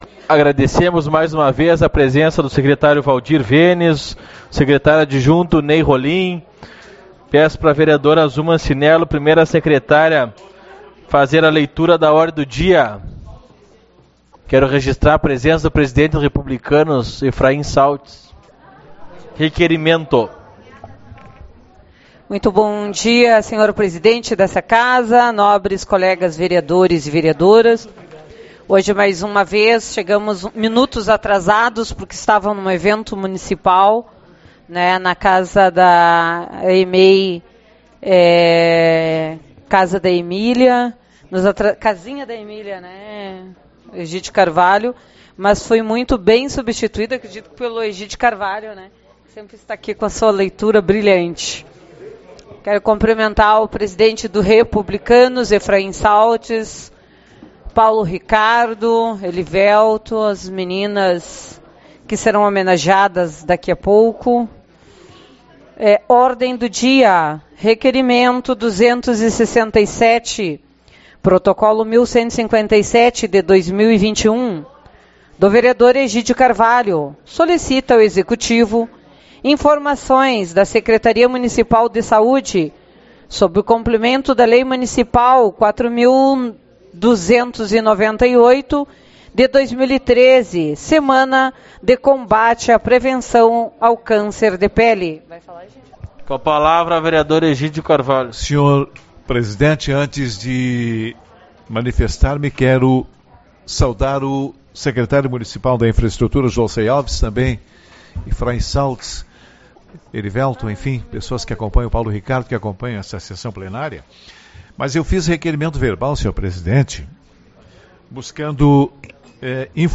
18/11 - Reunião Ordinária